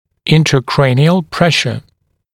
[ˌɪntrə’kreɪnɪəl ‘preʃə][ˌинтрэ’крэйниэл ‘прэшэ]внутричерепное давление